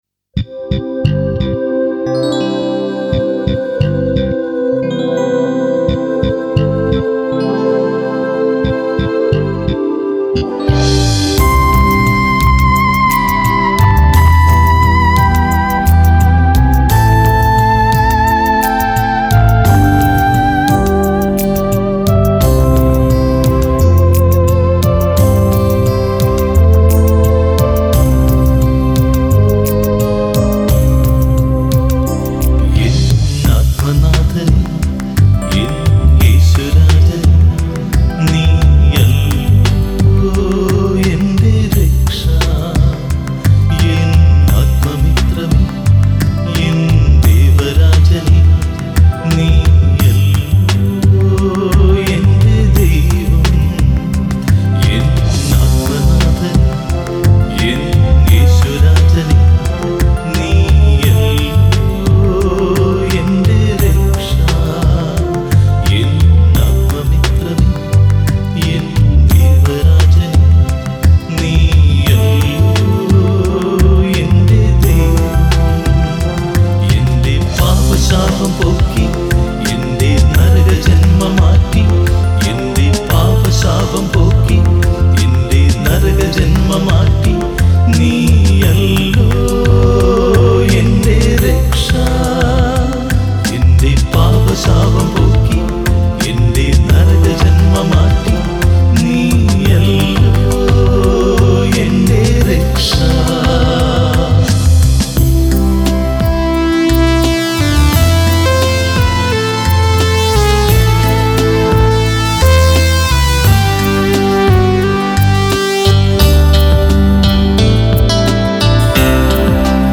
Keyboard Sequence
Flute
Tabala & Percussion